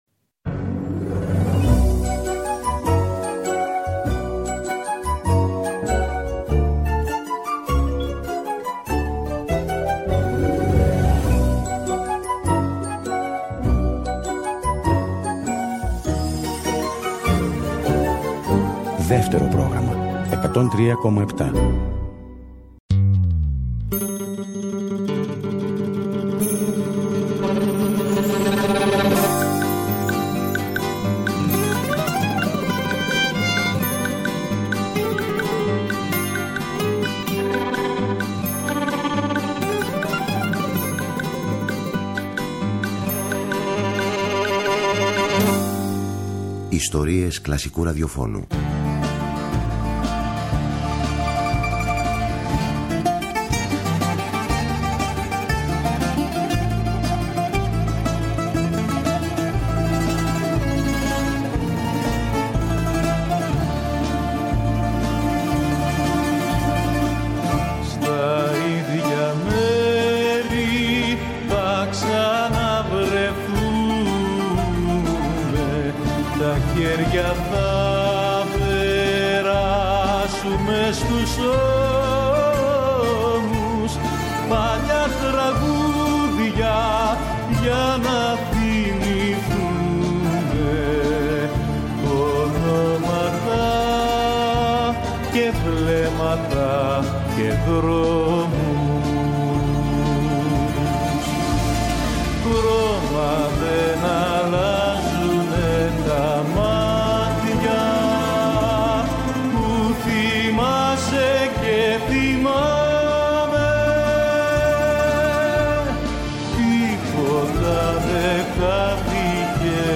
Μια ζεστή, φιλική και αποκαλυπτική συζήτηση με πολύ χιούμορ που δεν πρέπει να χάσετε…